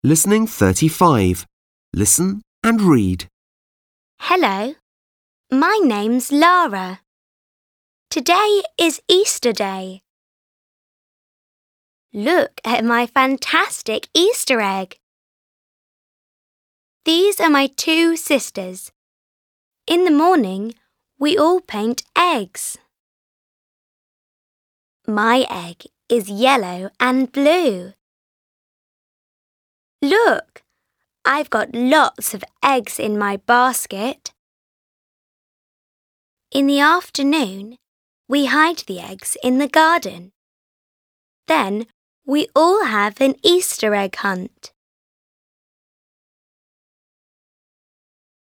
Pracowaliśmy dziś z książką Young Treetops 2. Kliknij play▶i posłuchaj jeszcze raz jak Lara opowiada jak obchodzi ze swoja rodziną Święta Wielkanocne w Wielkiej Brytanii.